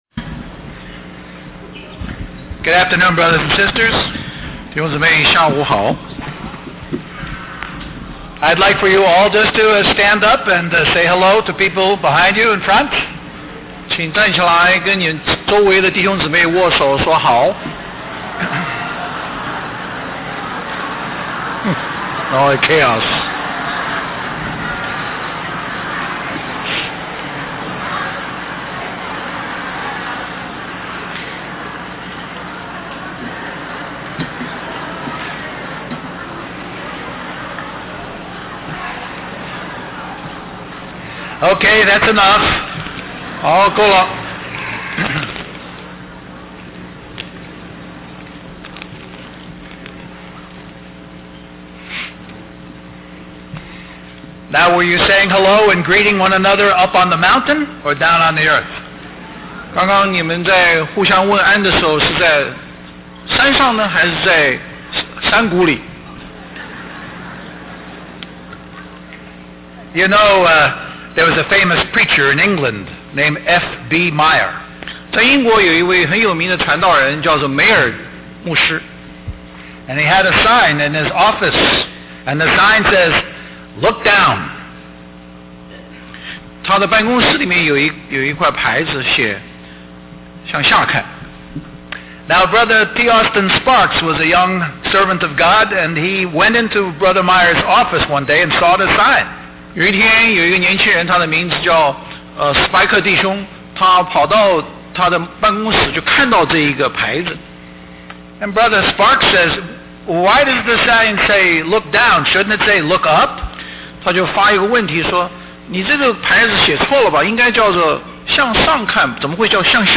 Special Conference For Service, Taipei, Taiwan